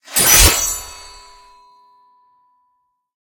bsword3.ogg